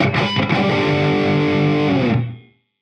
Index of /musicradar/80s-heat-samples/85bpm
AM_HeroGuitar_85-G01.wav